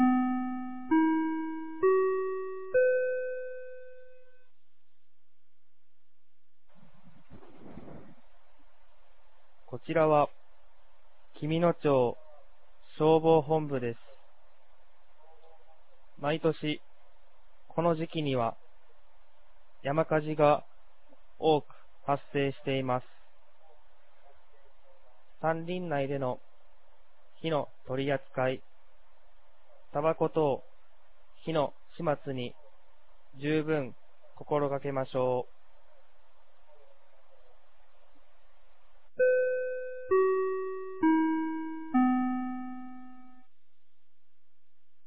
2022年05月14日 16時01分に、紀美野町より全地区へ放送がありました。